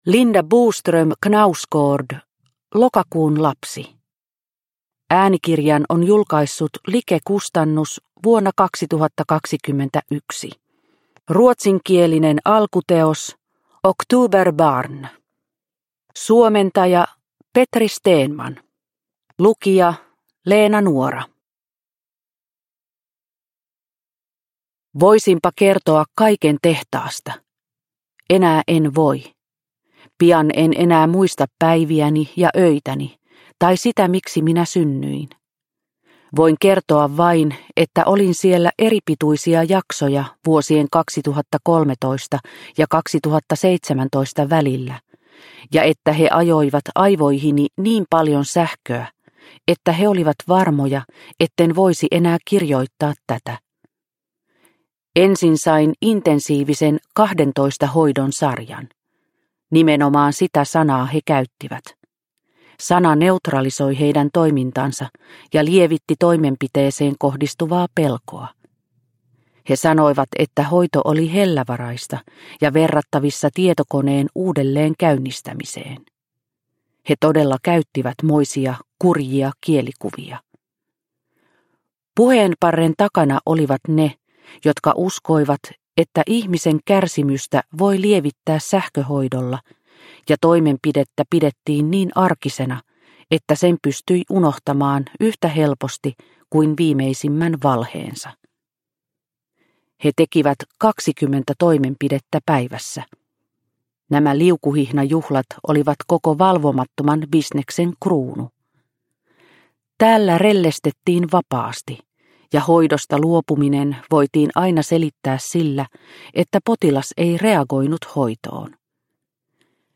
Lokakuun lapsi – Ljudbok – Laddas ner